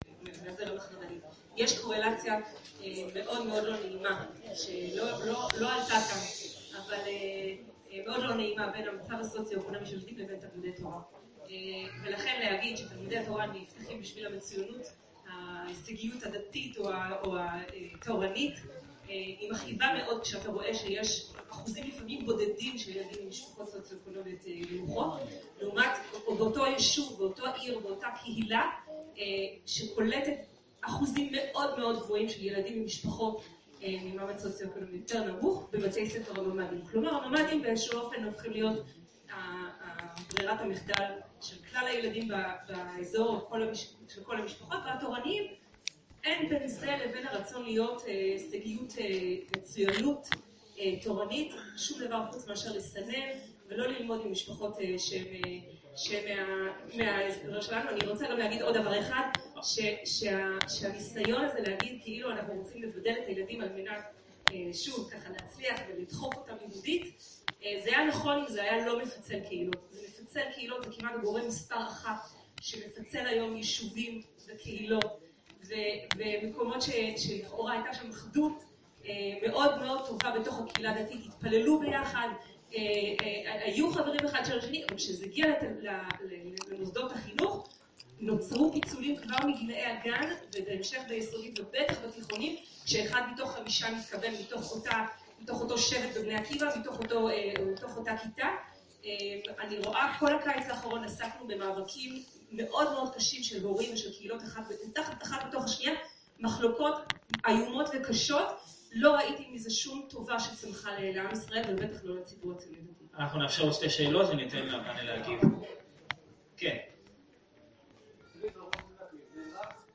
בדיון סוער שנערך בוועידת הבית היהודי, דנו מיטב ראשיה של הצינות הדתית בנושאים הקשורים לחינוך הדתי.